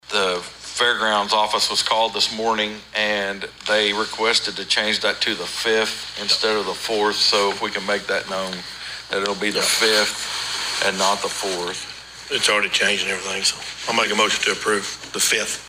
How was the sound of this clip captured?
The Board of Osage County Commissioners convened for a regularly scheduled meeting at the fairgrounds on Thursday for a standard meeting.